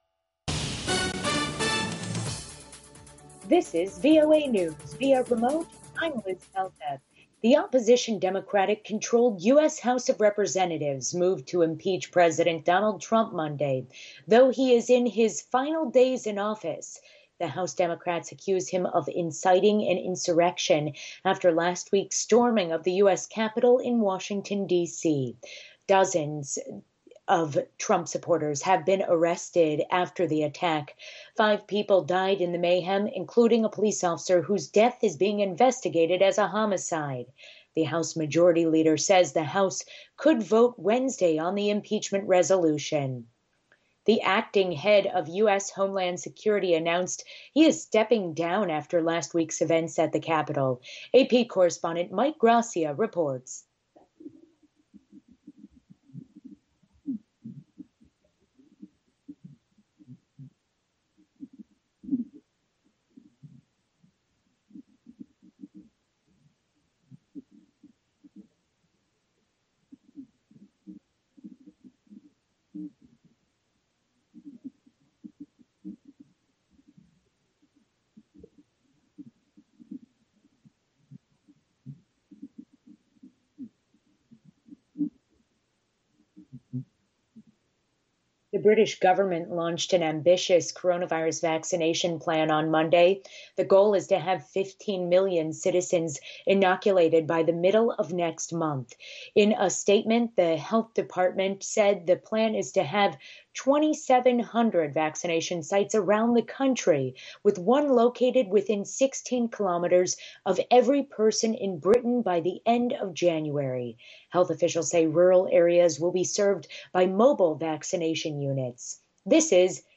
VOA Newscasts (2 Minute)